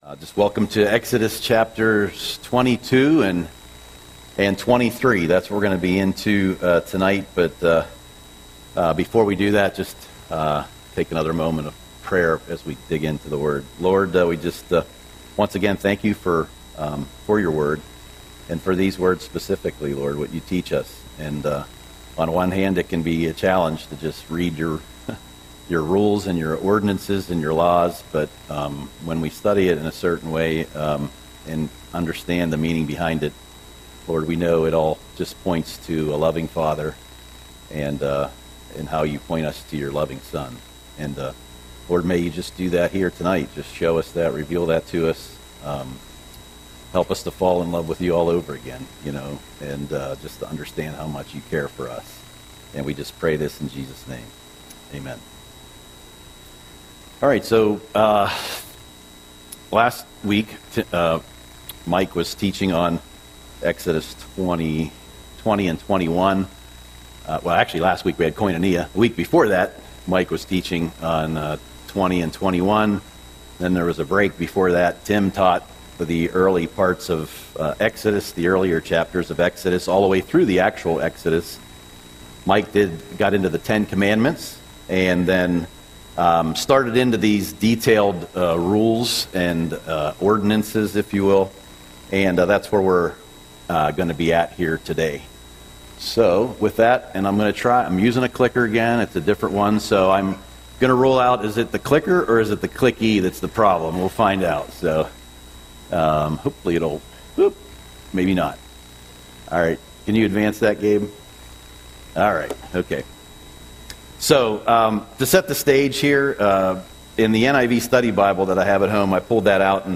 Audio Sermon - May 7, 2025